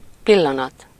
Ääntäminen
Synonyymit instant Ääntäminen France: IPA: [mɔ.mɑ̃] Haettu sana löytyi näillä lähdekielillä: ranska Käännös Ääninäyte Substantiivit 1. pillanat Muut/tuntemattomat 2. perc 3. pont Suku: m .